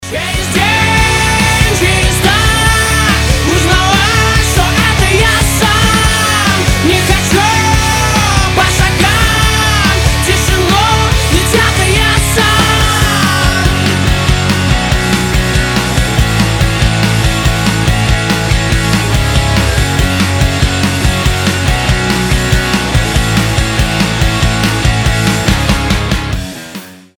мужской вокал
громкие
Драйвовые
Alternative Rock
indie rock
бодрые
русский рок